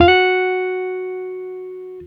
Guitar Slid Octave 19-F#3.wav